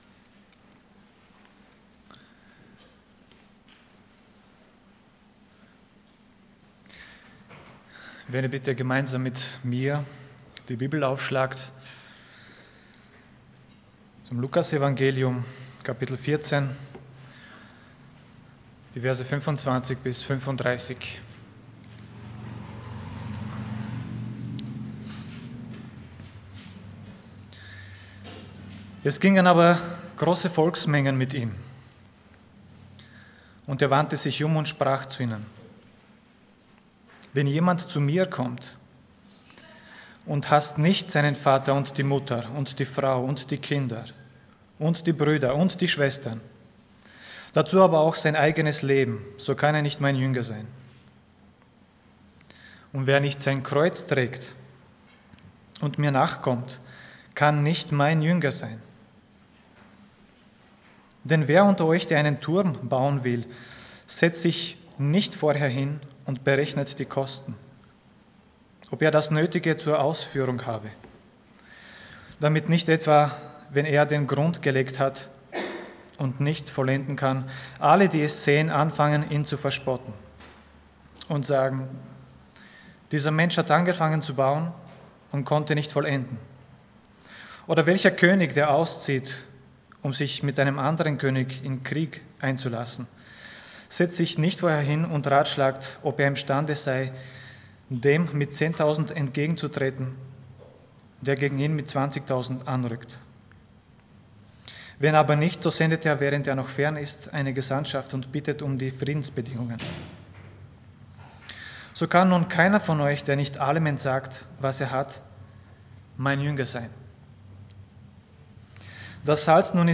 Passage: Luke 14:25-35 Dienstart: Sonntag Morgen